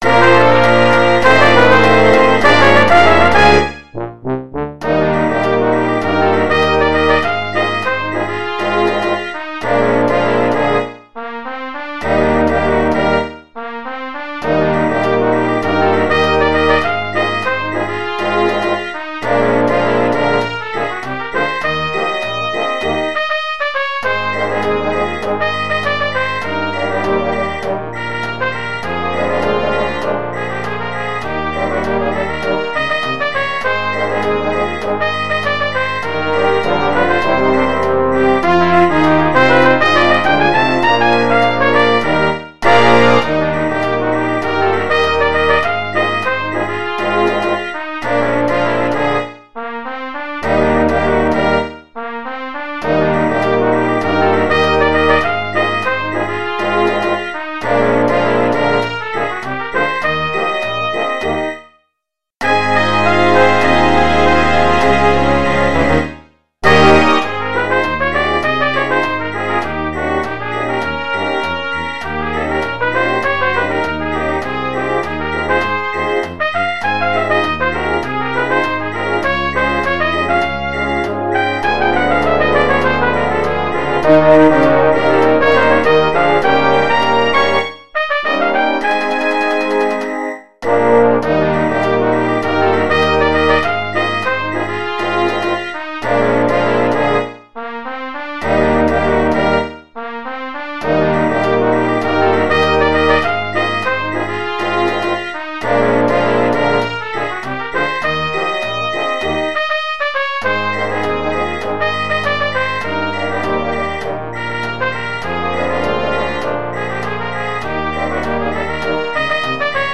The upbeat, ragtime cornet solo with brass band